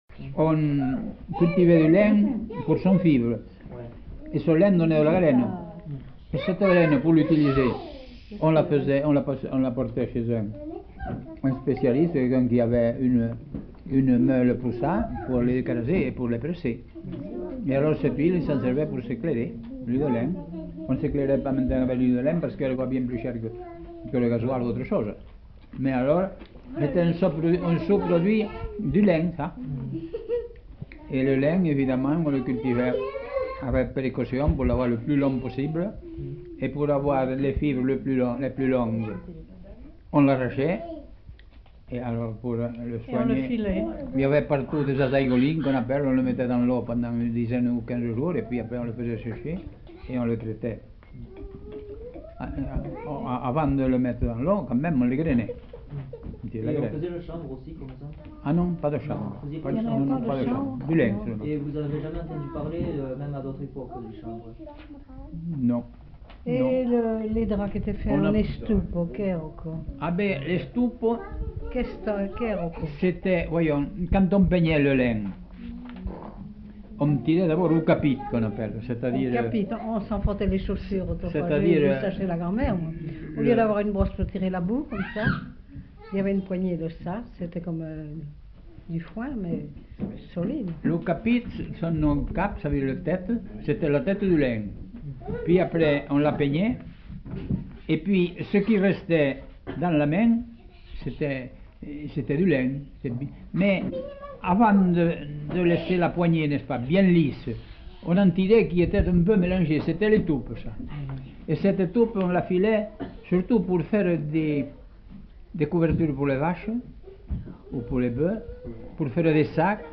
Lieu : Montaut
Genre : témoignage thématique